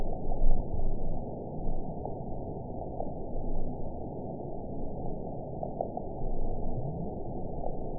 event 921749 date 12/18/24 time 19:26:24 GMT (4 months, 2 weeks ago) score 8.43 location TSS-AB03 detected by nrw target species NRW annotations +NRW Spectrogram: Frequency (kHz) vs. Time (s) audio not available .wav